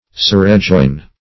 Meaning of surrejoin. surrejoin synonyms, pronunciation, spelling and more from Free Dictionary.
Search Result for " surrejoin" : The Collaborative International Dictionary of English v.0.48: Surrejoin \Sur`re*join"\, v. i. [Pref. sur + rejoin.] (Law) To reply, as a plaintiff to a defendant's rejoinder.